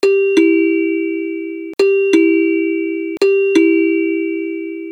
notification-sound1.mp3